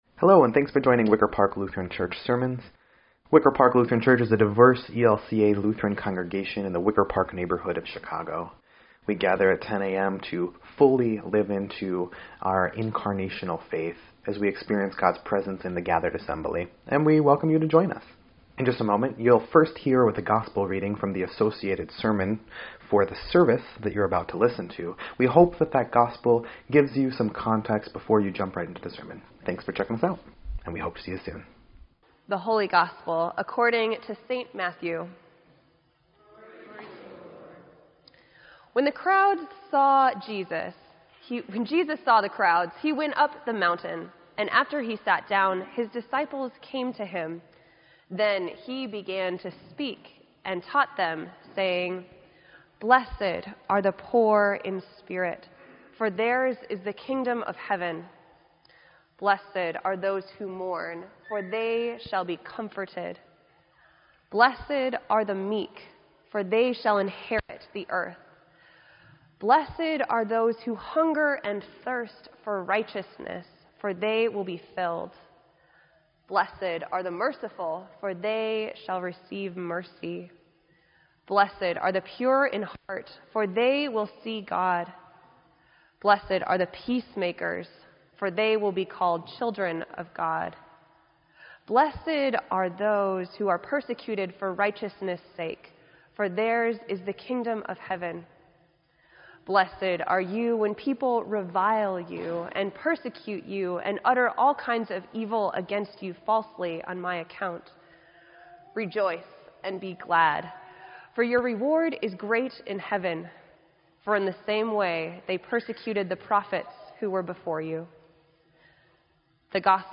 Sermon_11_5_17_EDIT.mp3